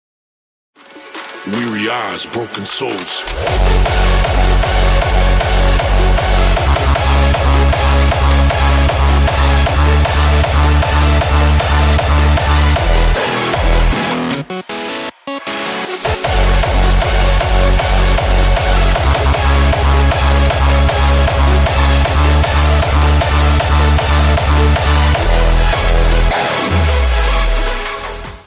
Crunchy kicks lovers somewhere ?